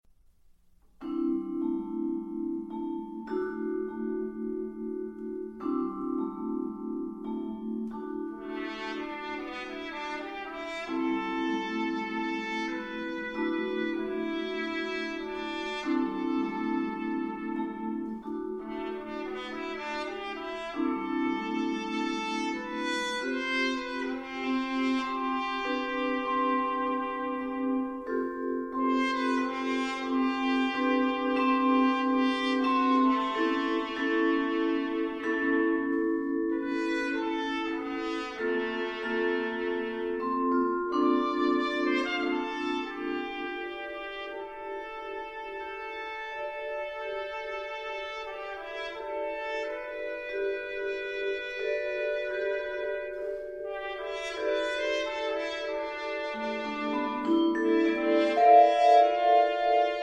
trumpets